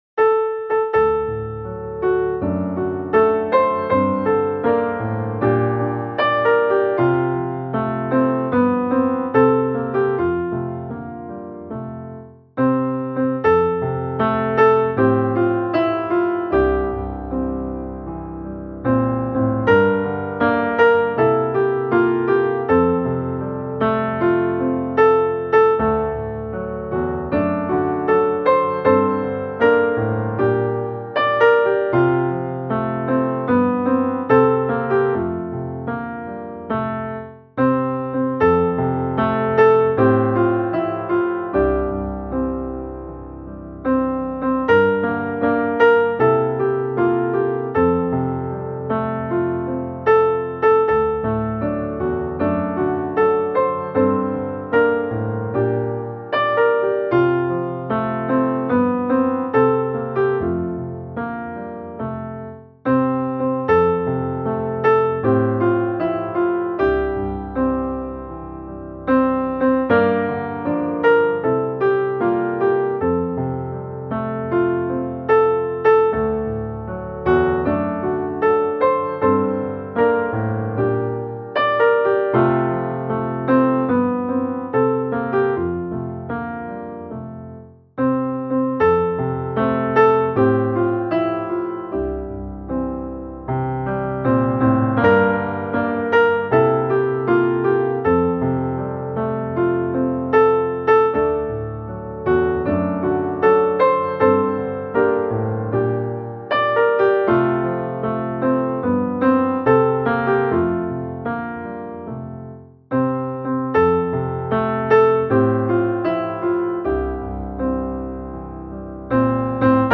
Är det sant att Jesus är min broder - musikbakgrund